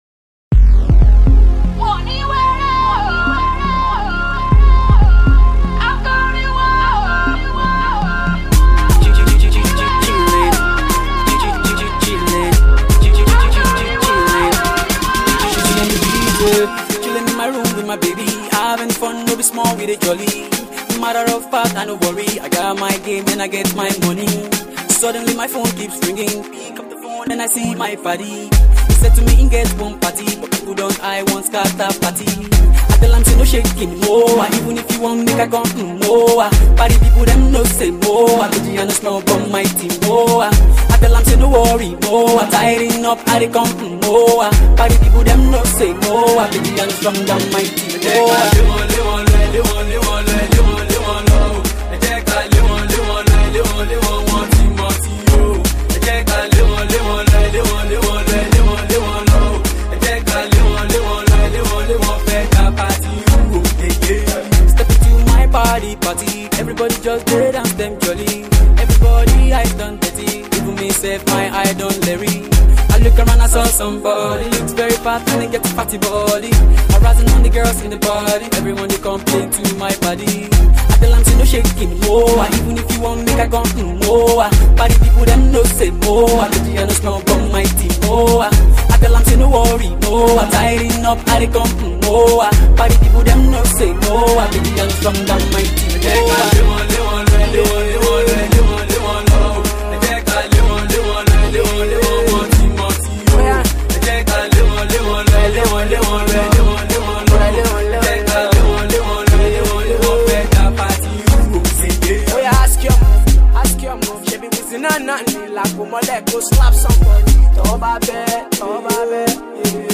quite the catchy tune
croons more aggressively on this track